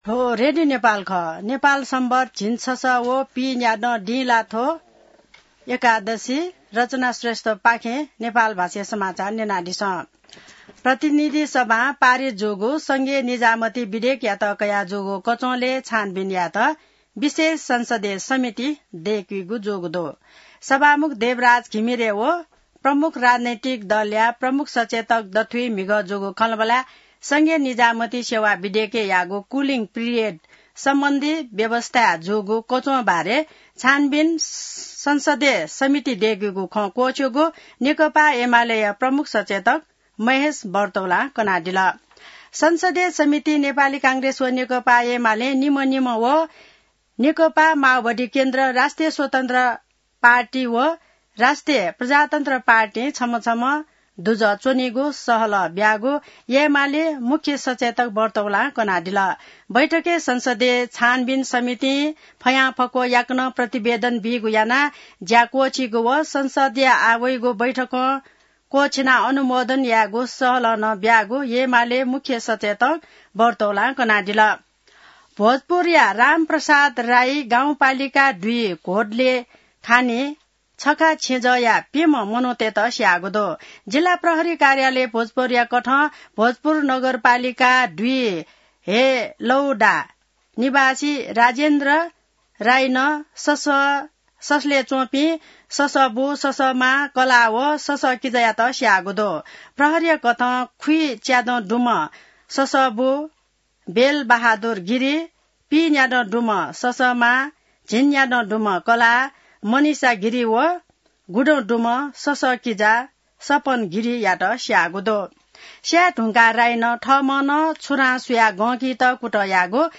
नेपाल भाषामा समाचार : २२ असार , २०८२